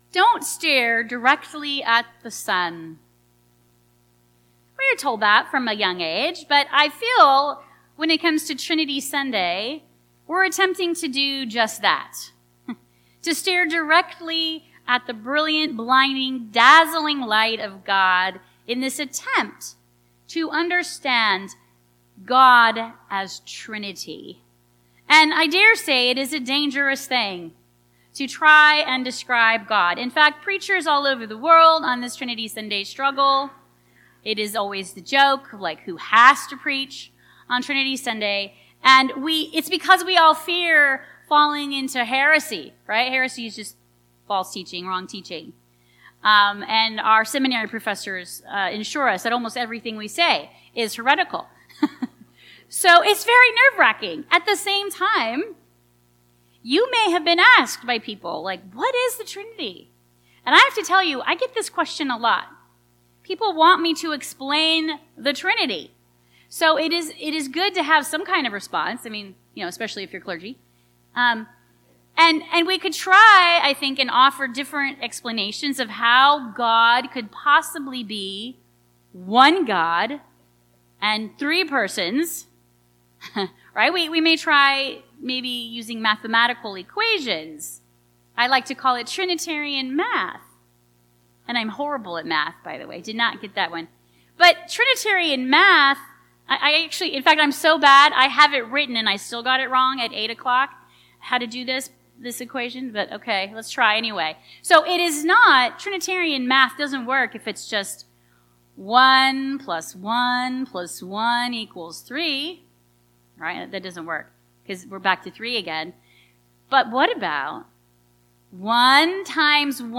Sunday's Sermon